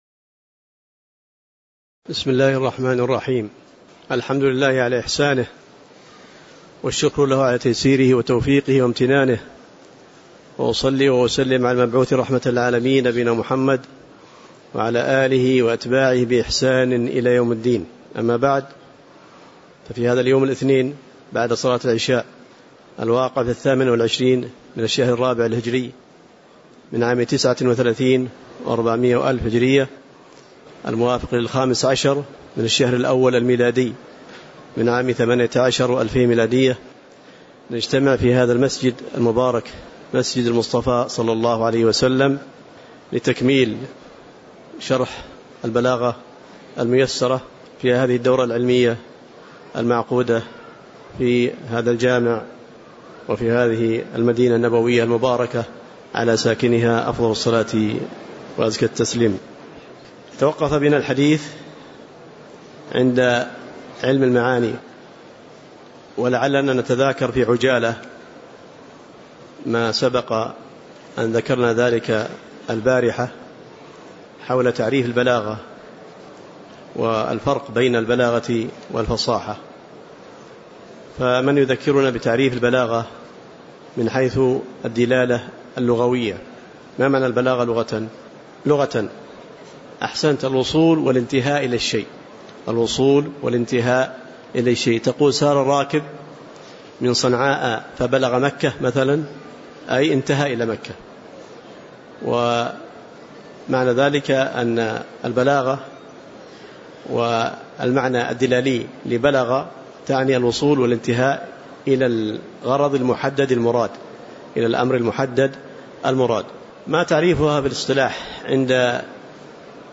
تاريخ النشر ٢٨ ربيع الثاني ١٤٣٩ هـ المكان: المسجد النبوي الشيخ